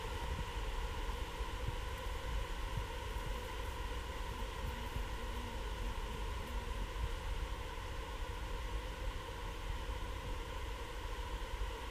На этой странице собраны разнообразные звуки мошек: от тихого жужжания до назойливого писка.
Шепот роя мошек над болотом